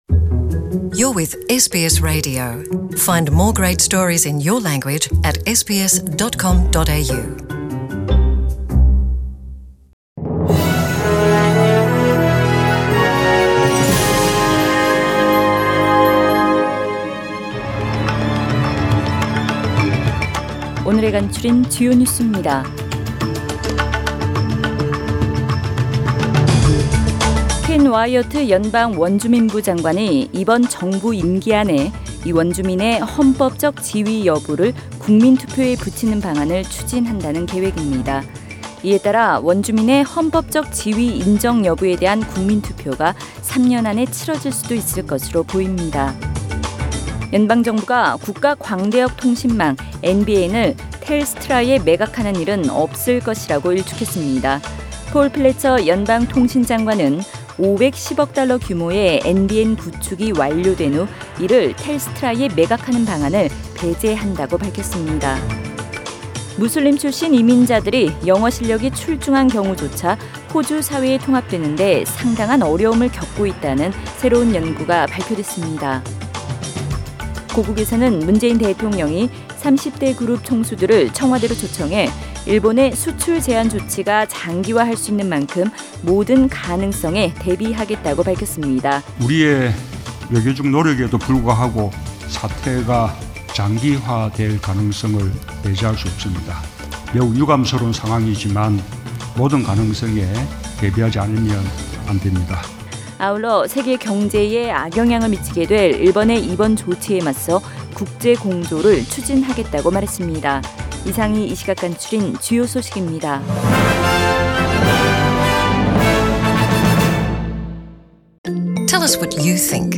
2019년 7월 10일 수요일 저녁의 SBS Radio 한국어 뉴스 간추린 주요 소식을 팟 캐스트를 통해 접하시기 바랍니다.